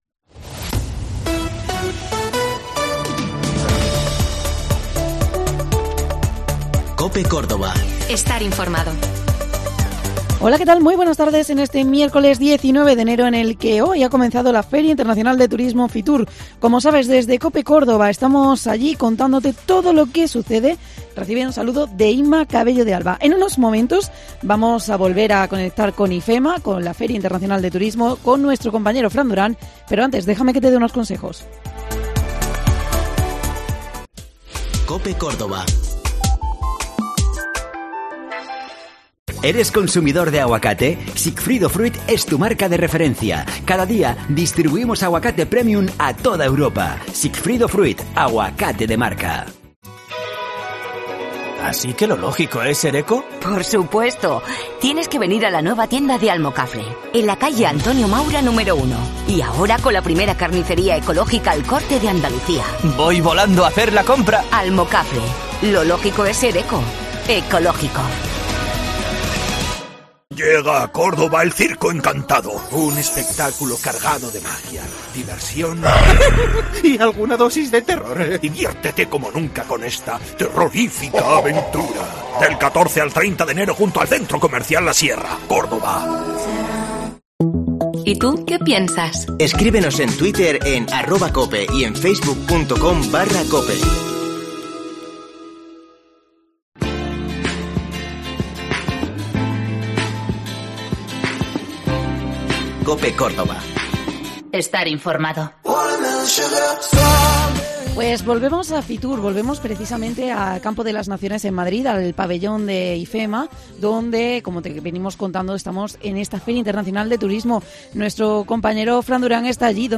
Hoy nos vamos hasta IFEMA donde te vamos a contar todo lo relativo a FITUR: Entrevistamos a Antonio Ruiz, presidente de la Diputación de Córdoba